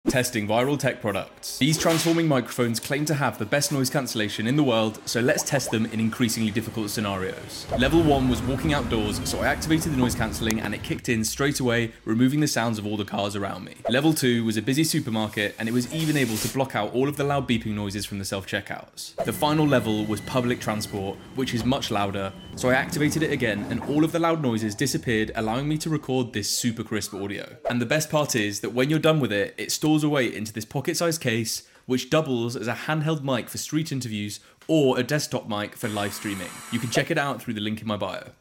This is the worlds first transforming wireless microphone from Boya Audio 🤯 I tested it’s noise cancelling capabilities and the results were insane!